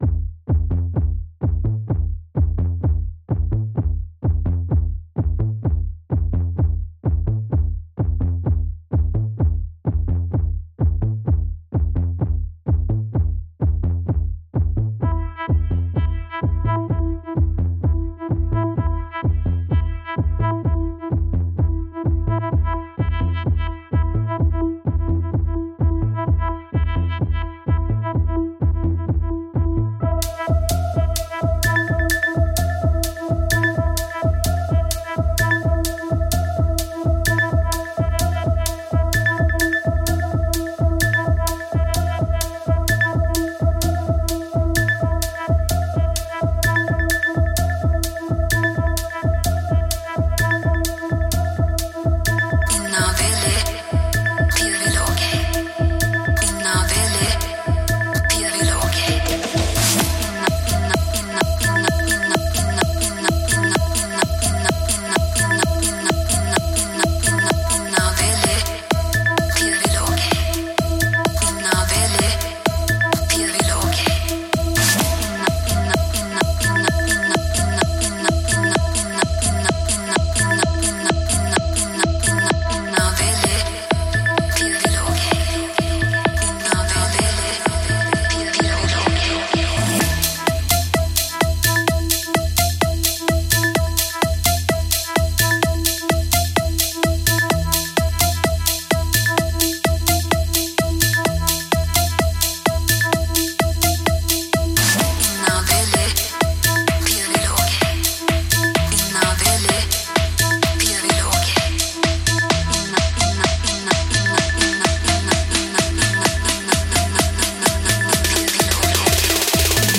Musique dynamique libre de droit pour vos projets.